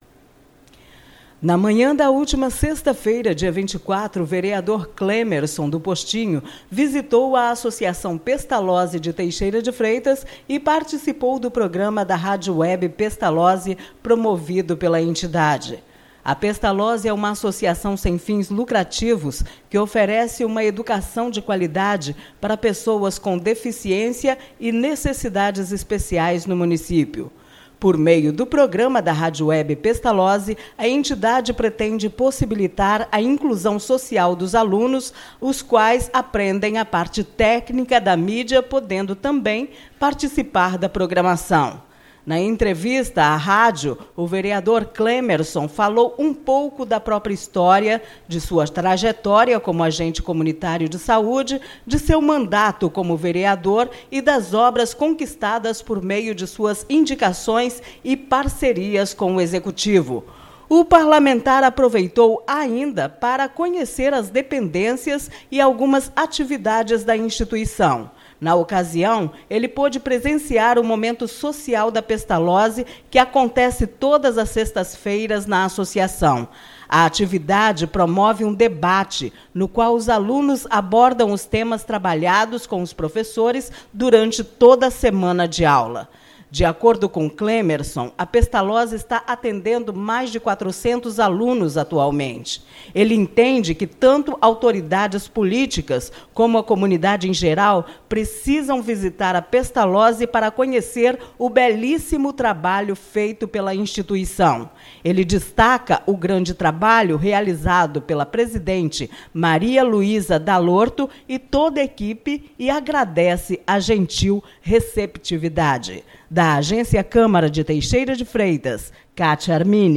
Audio-vereador-Clemerson-destaca-a-importancia-do-trabalho-realizado-pela-Pestalozzi.mp3